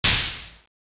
Goofy Sound Effects
Snap Hit
SnapHit.WAV